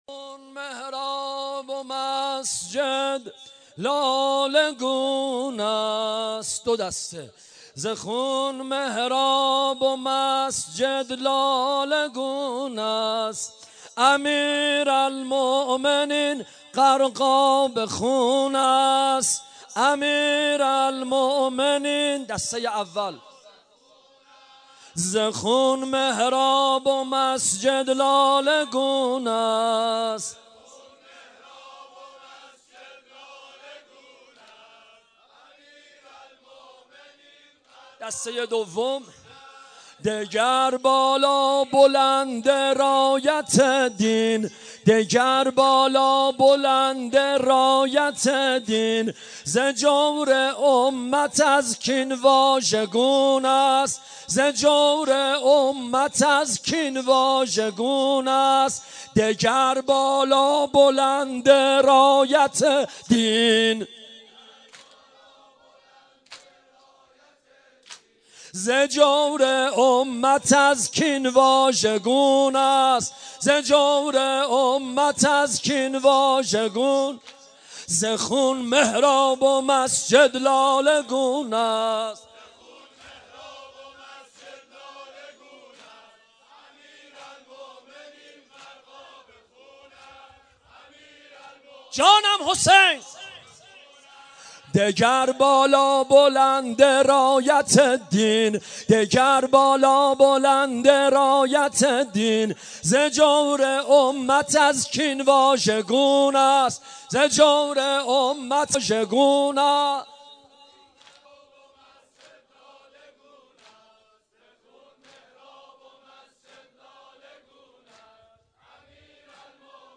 شب بیست و یکم رمضان - هیئت محبین اهل بیت علیهاالسلام
دودمه | زخون محراب و مسجد لاله گون است